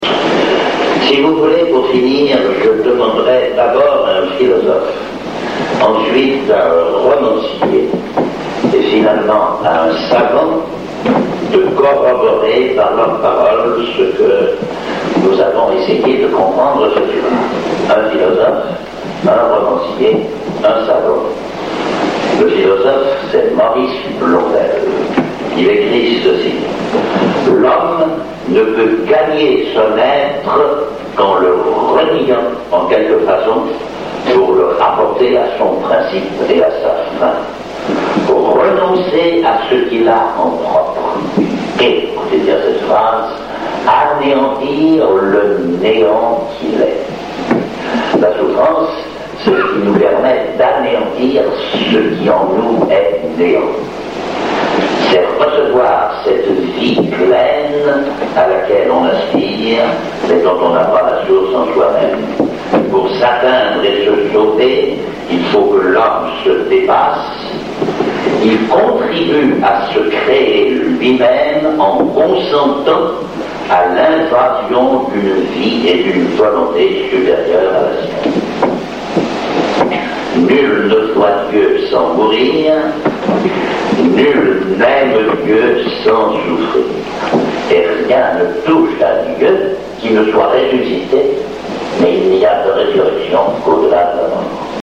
Conférence du Père François Varillon sur le problème du mal, suite (5/5)